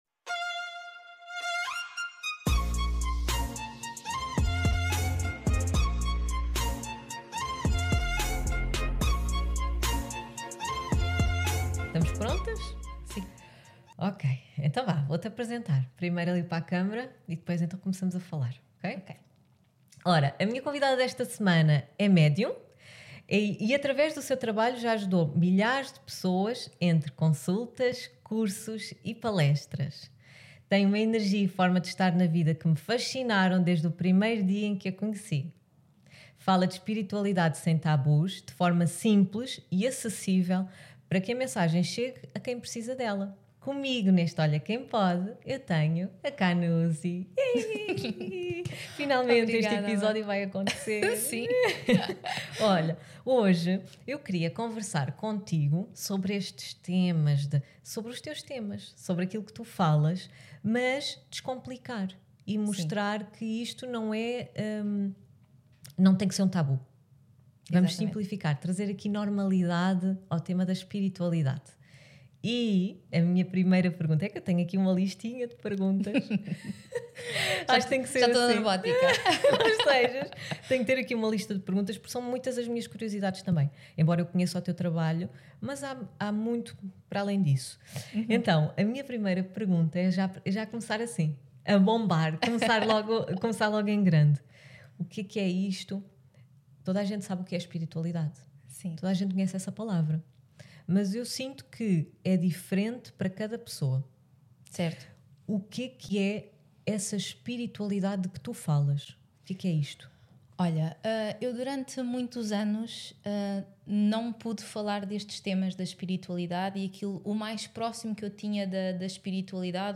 trouxe-nos vários temas dentro da espiritualidade, de forma leve e descontraída